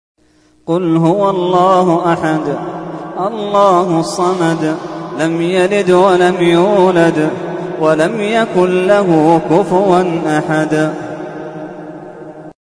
تحميل : 112. سورة الإخلاص / القارئ محمد اللحيدان / القرآن الكريم / موقع يا حسين